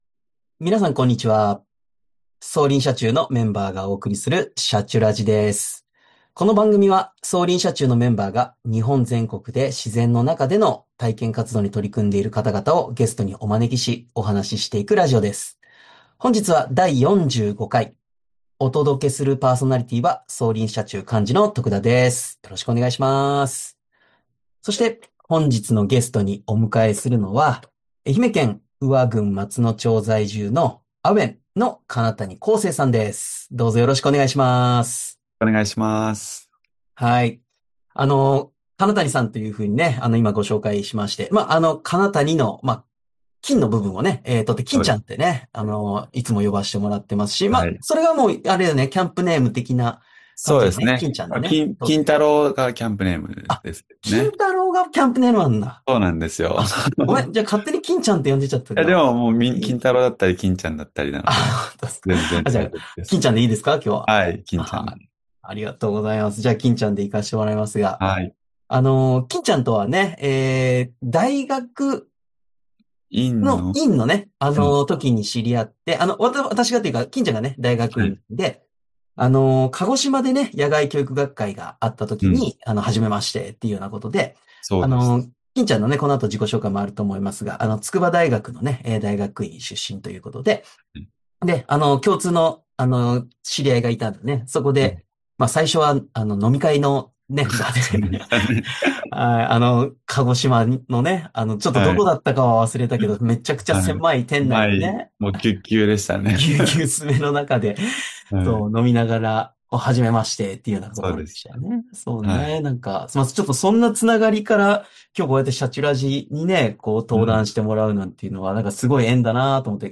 【今回のゲストスピーカー】